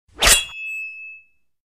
Knife Sound Effects ringtone free download